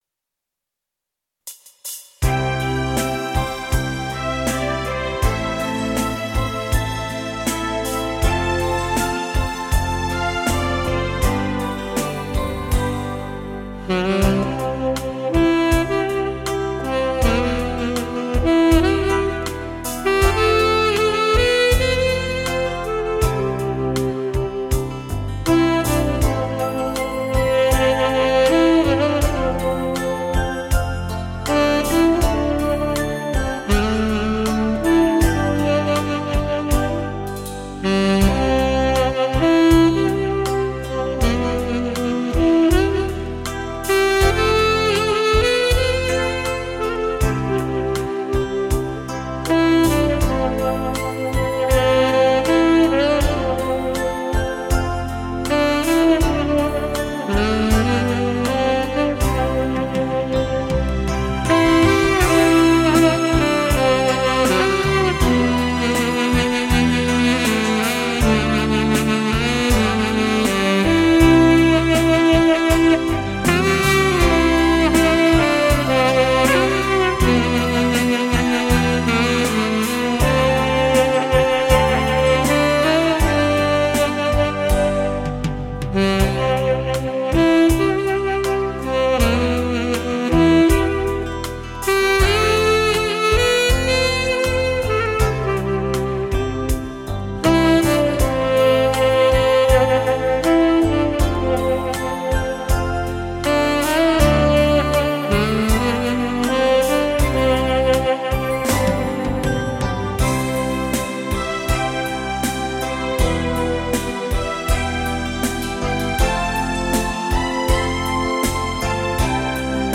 经典自然音乐 休闲生活享受
萨克斯